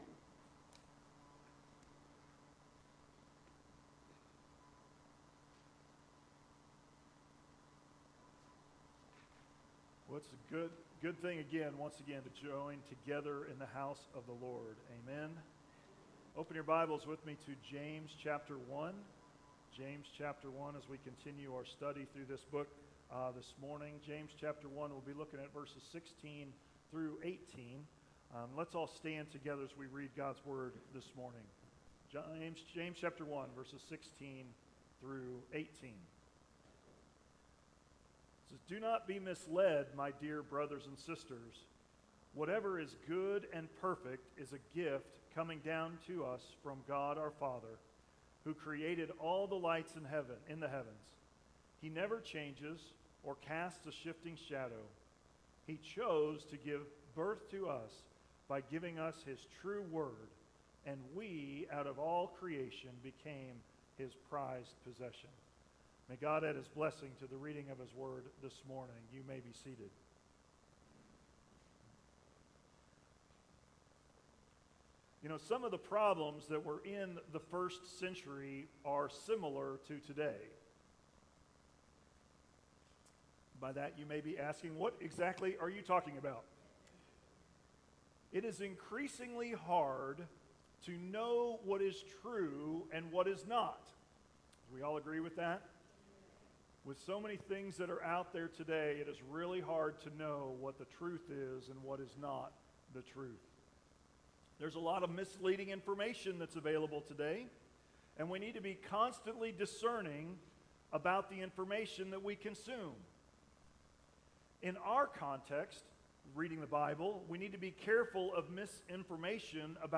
Sermons | Centennial Baptist Church